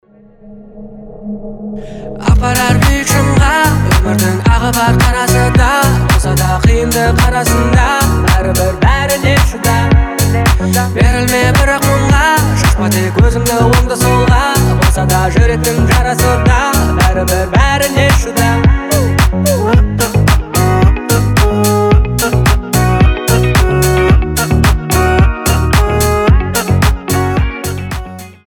• Качество: 320, Stereo
красивый мужской голос
казахские